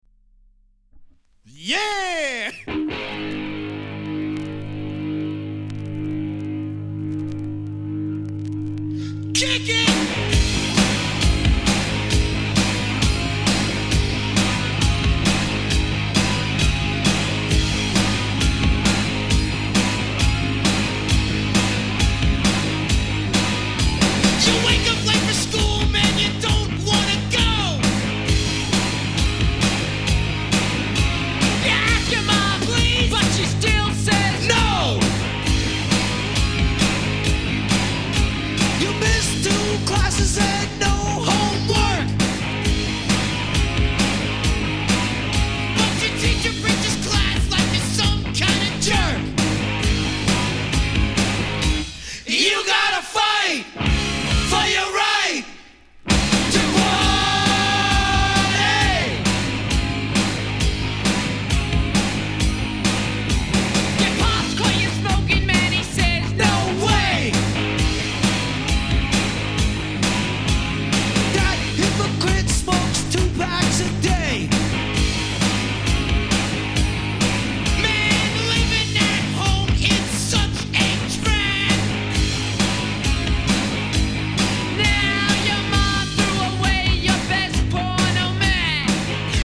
category Rap & Hip-Hop